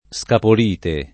scapolite [ S kapol & te ] s. f. (min.)